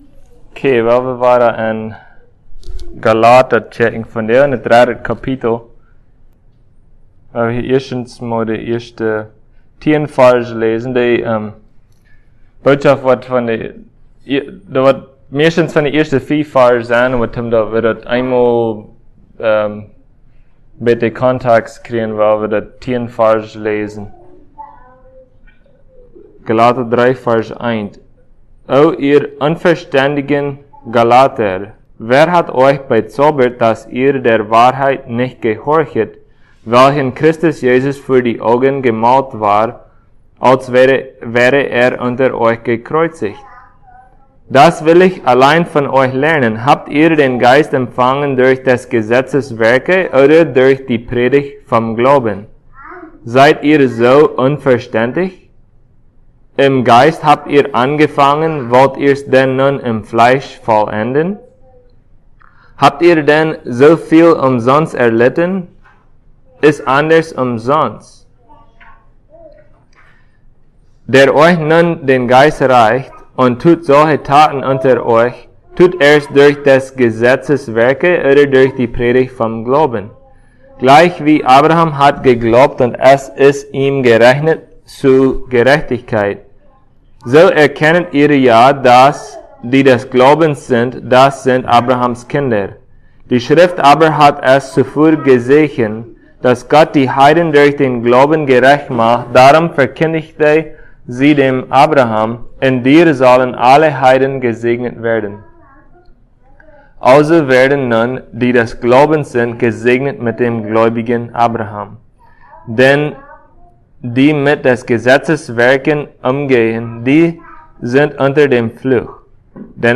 Passage: Galatians 3:1-6 Service Type: Sunday Plautdietsch « The Tabernacle